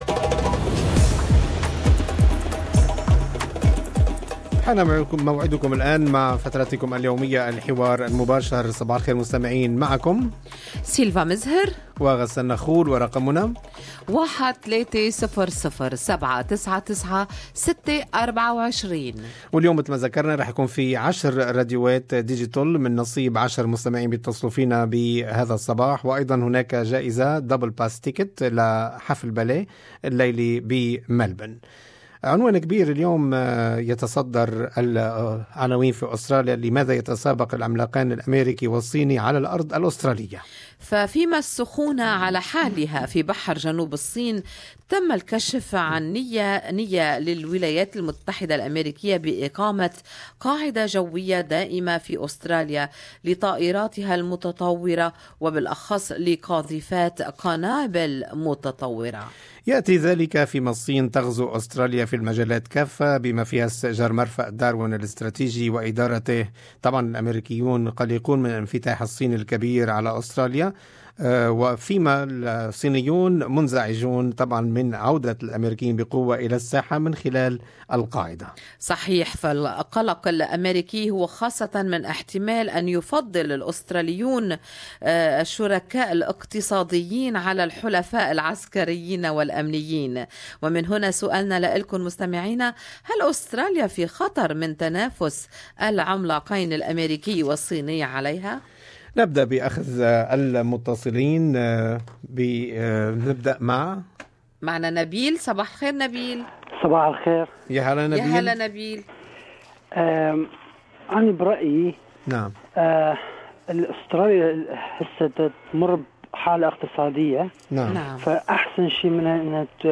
اراء المستمعين في فقرة الحوار المباشر